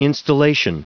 Prononciation du mot instillation en anglais (fichier audio)
Prononciation du mot : instillation